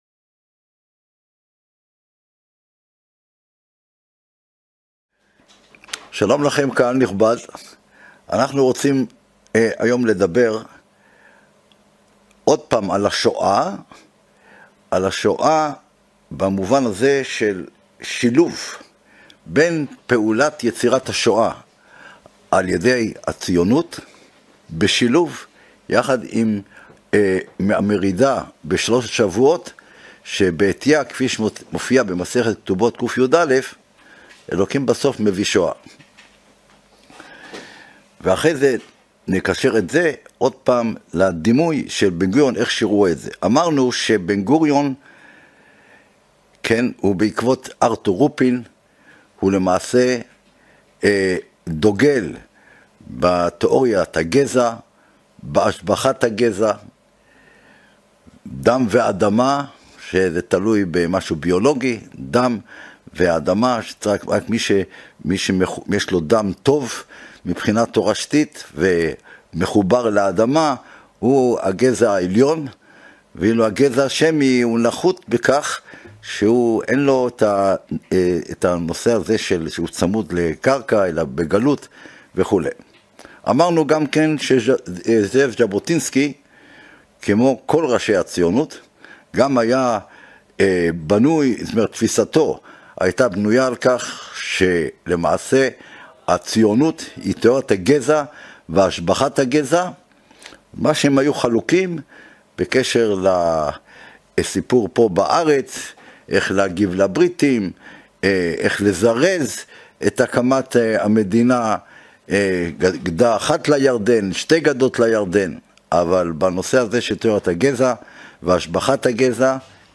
הרצאה 11 - תרבות ותקשורת - ביבי השופכין של החברה הישראלית